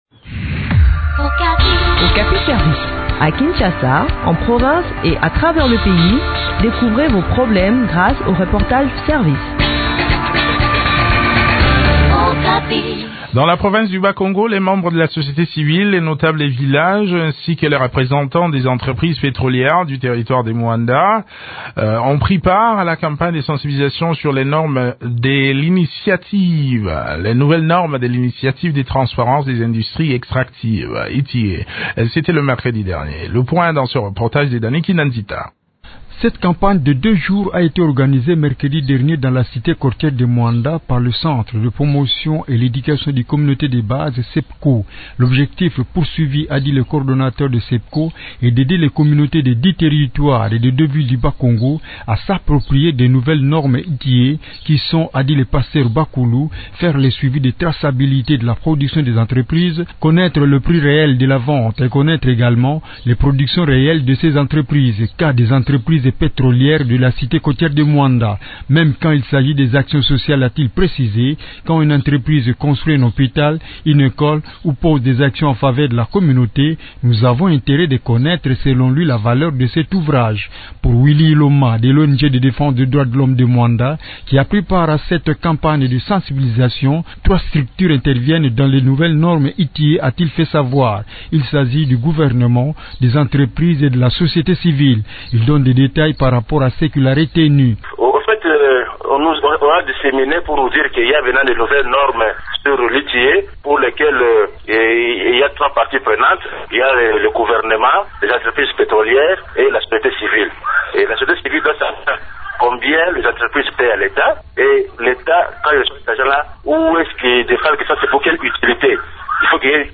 Le point sur le déroulement de cette campagne de sensibilisation dans cet entretien